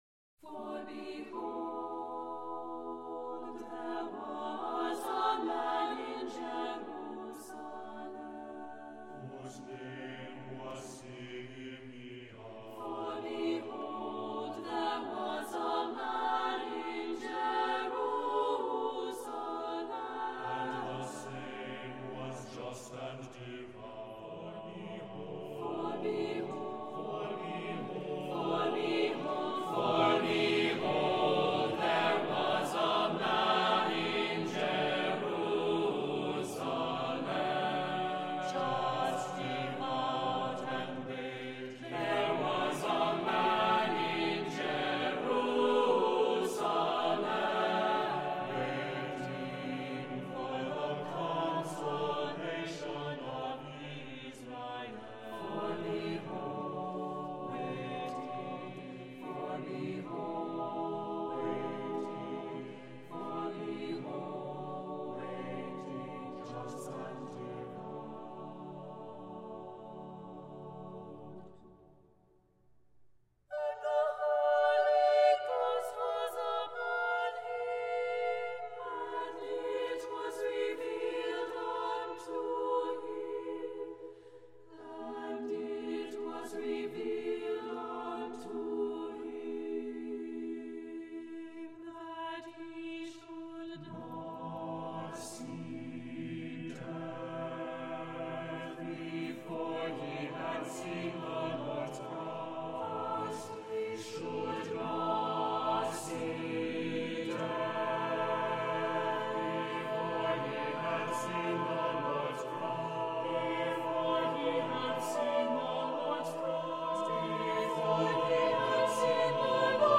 for A Cappella Chorus